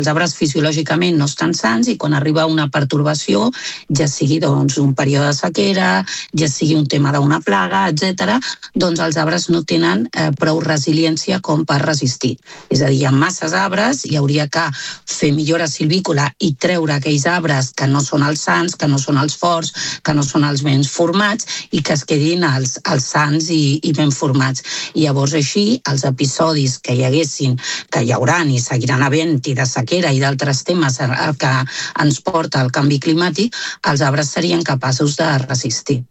Segons ha explicat la consellera de Medi Natural del Consell Comarcal del Maresme, Marta Gómez, a l’entrevista de RCT, el problema de fons és l’excés de densitat forestal.